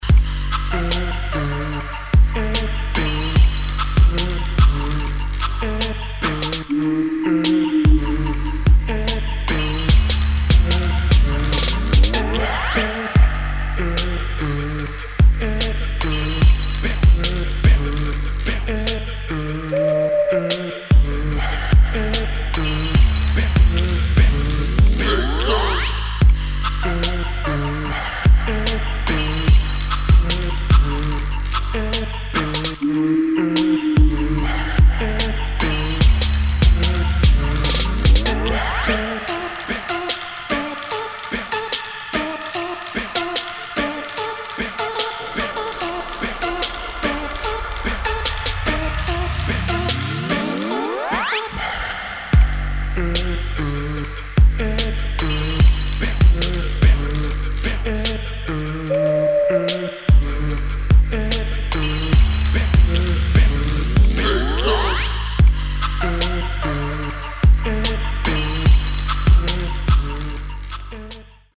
DOWNBEAT / TRAP